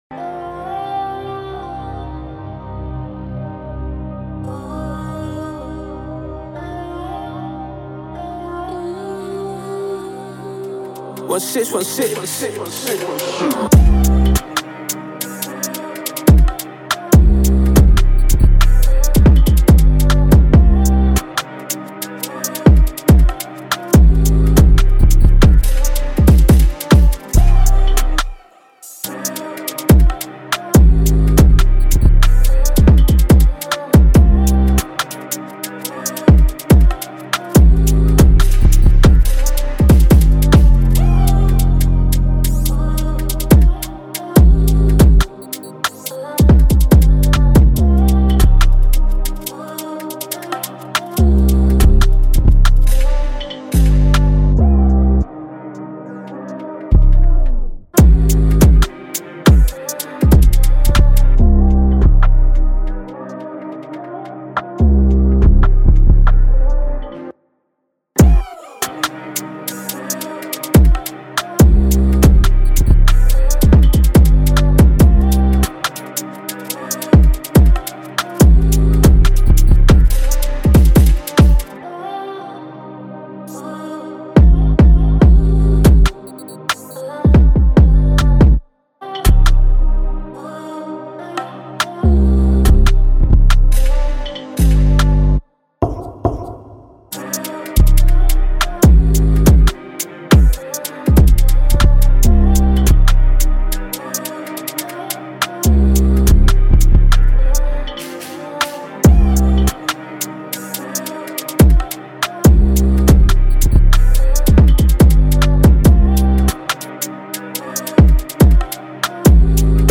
UK Drill Instrumentals